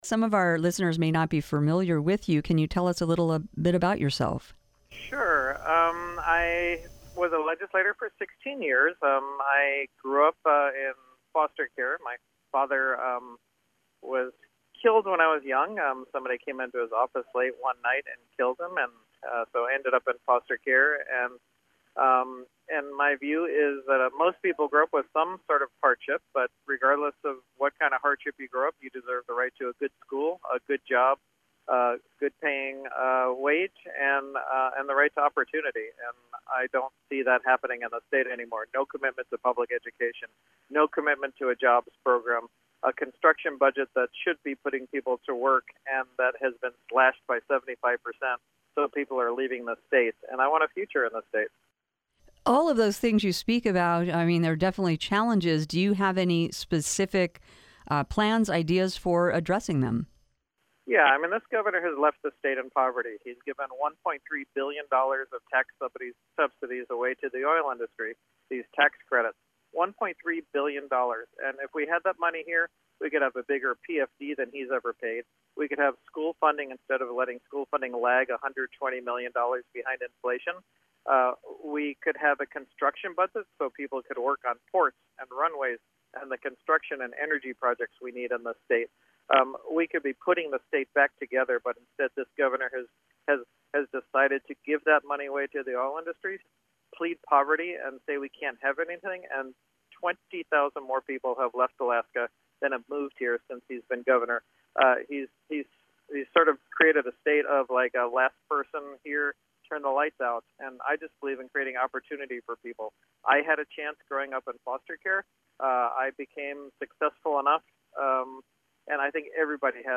Democratic gubernatorial candidate Les Gara spoke with KRBD by phone as he was traveling for a campaign stop in Ketchikan Wednesday night. He discussed the economy, education, job creation and the Alaska Marine Highway System.